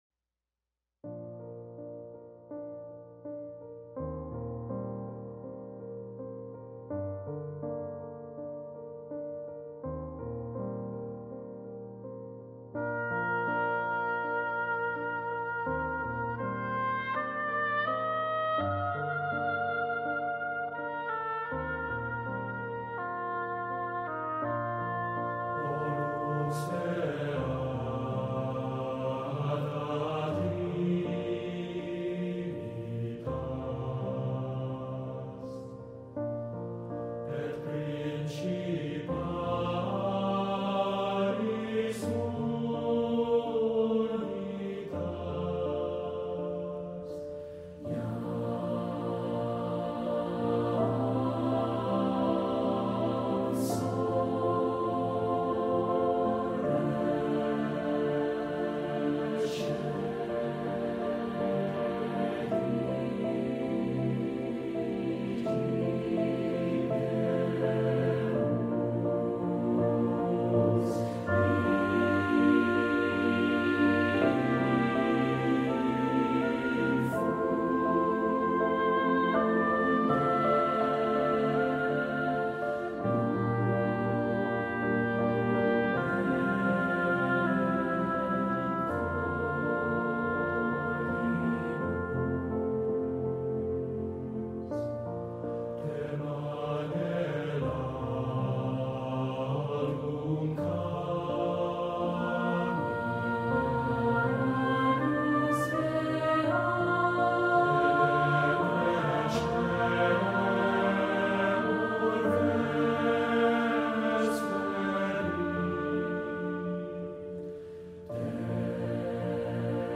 Voicing: SATB, Oboe and Piano